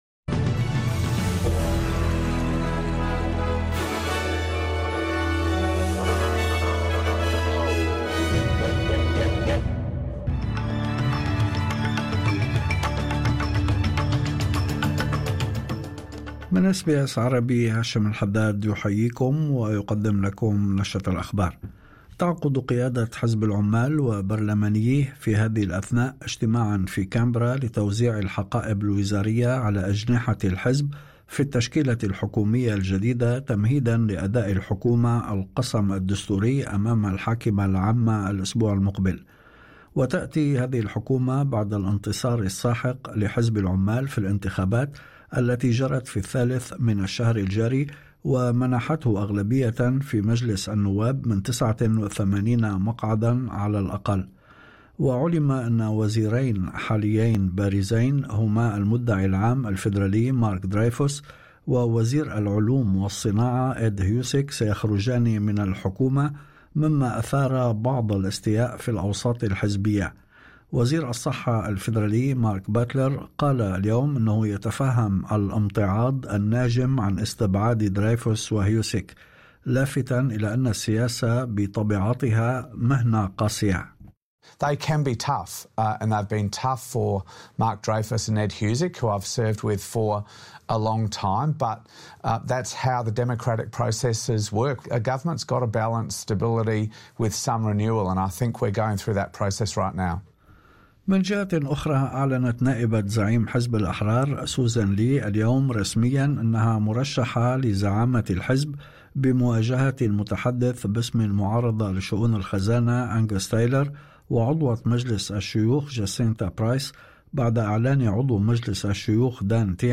نشرة أخبار الظهيرة 09/05/2025